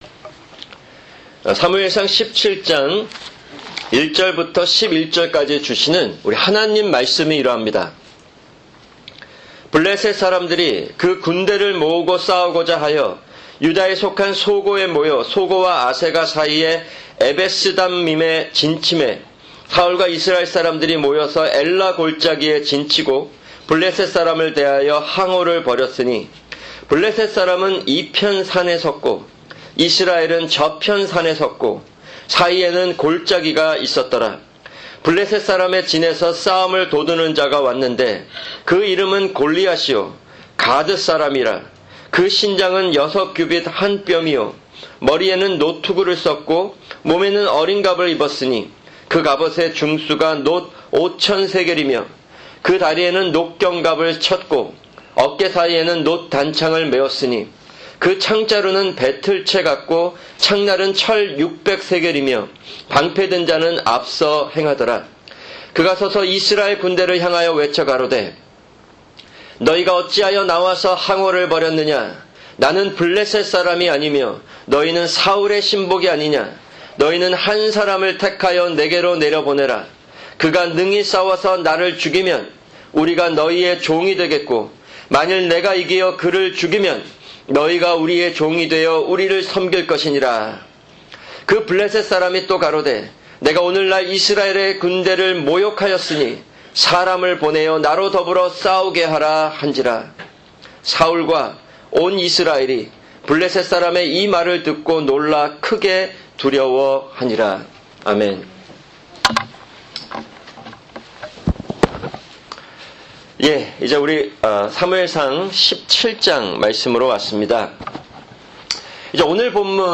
[주일 설교] 사무엘상(46) 17:1-11(1)